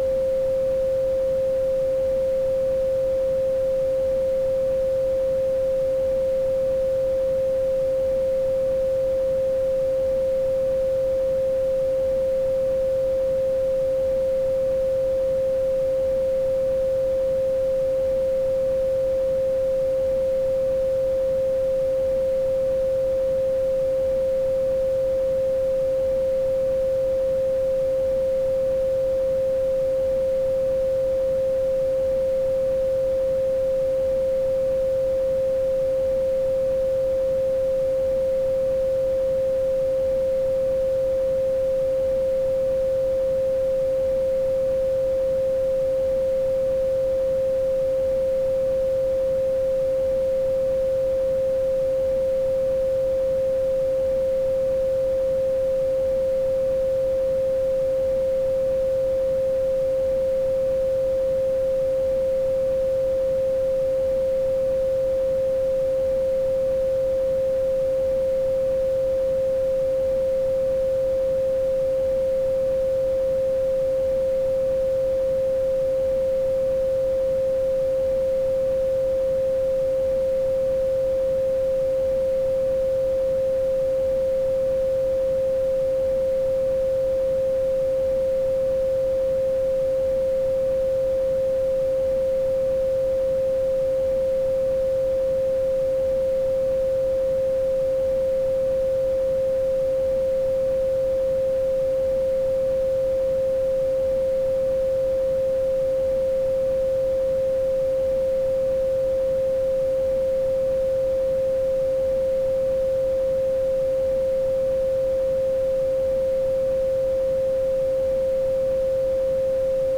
RESTAURAÇÃO DO DNA Frequência 528 Hz + Ruído Marrom: Restauração Celular O uso de frequências sonoras para fins terapêuticos é uma prática milenar que ganhou nova força com os avanços da neurociência e da física moderna.
O ruído marrom é uma base sonora grave, encorpada e constante, que acalma o sistema nervoso e ajuda o cérebro a entrar em estados profundos de relaxamento . Ele cria um "colchão sonoro" perfeito para receber a frequência terapêutica de 528 Hz.
DNA_BrownNoise.ogg